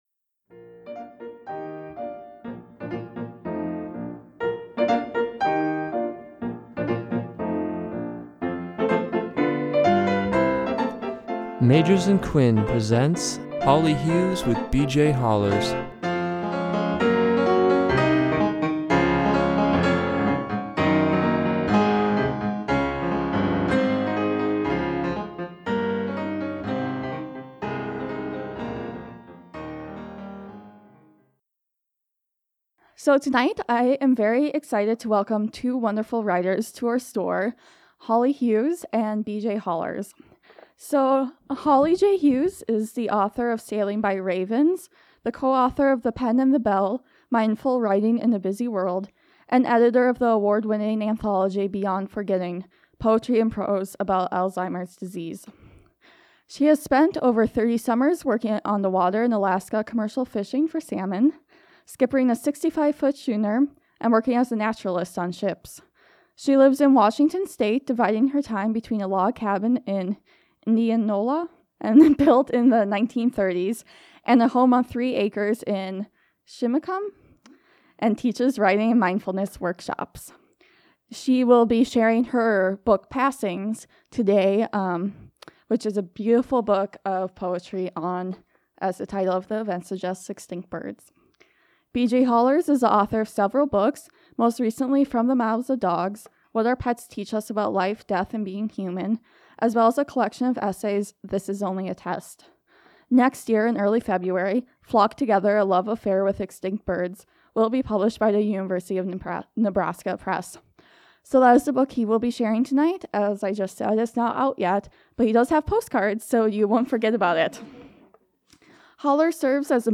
Reflections on Extinct Birds: A Poetry and Prose Reading
Recorded on September 4, 2016 at Magers and Quinn Booksellers.